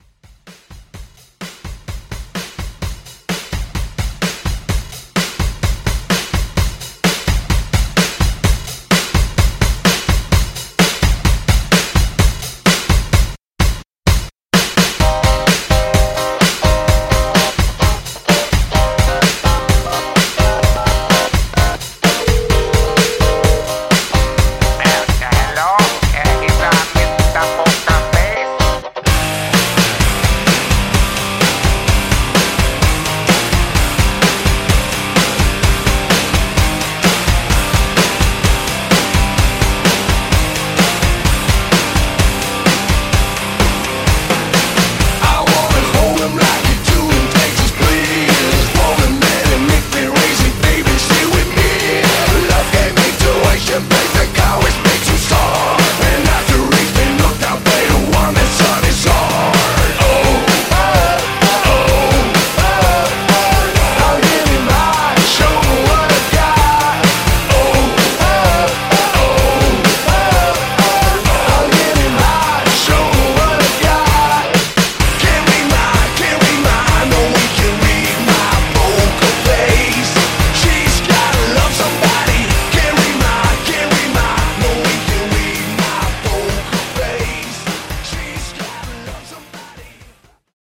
Rock Metal Red-Drum)Date Added